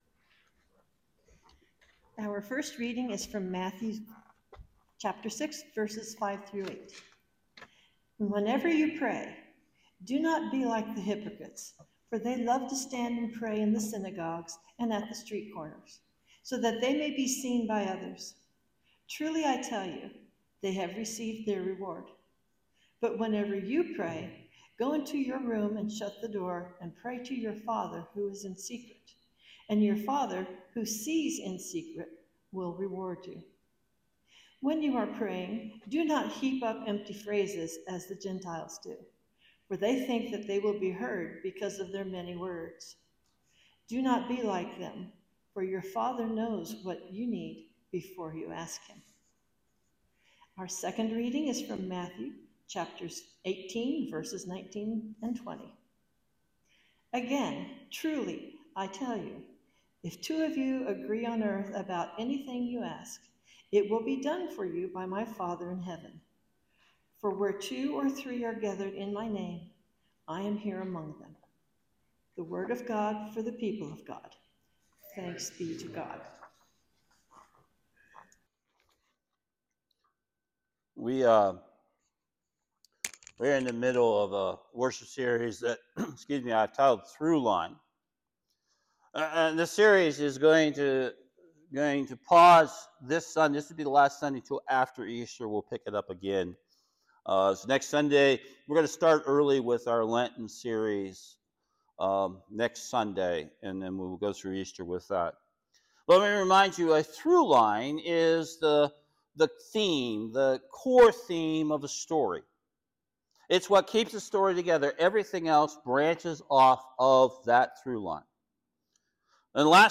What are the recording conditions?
Service Type: Sunday Worship This is the third message in our “Throughline” series.